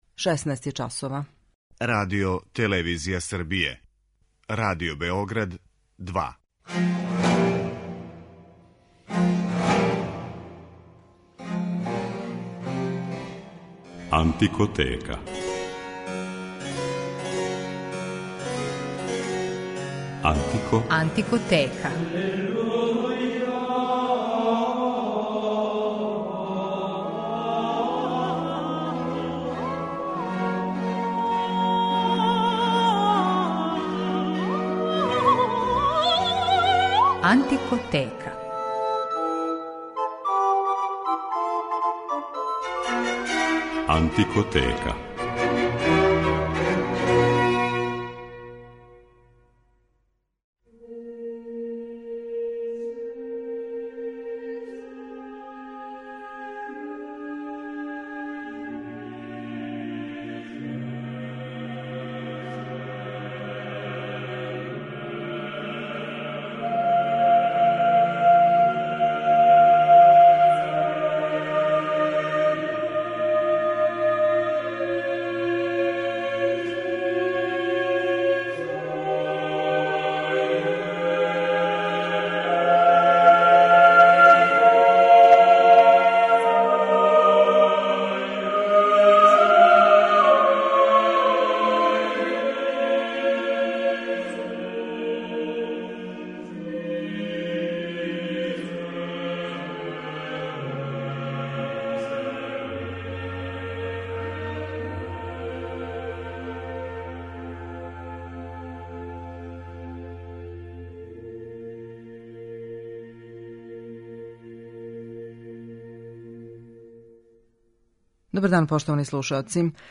Данашња Антикотека је посвећена једном од најбољих вокалних ансамбала на свету The Sixteen, који од 1986. године има и оркестар.
Ове врхунске британске музичаре представићемо у разноврсном репертоару и слушаћете их како изводе композиције Виљема Берда, Томаса Талиса, Антонија Калдаре, Ђованија Пјерлуиђија да Палестрине и Георга Фридриха Хендл.